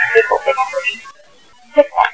I took along my tape recorder and digital camera.
I was surprised to hear a lady spirit - ghost saying.....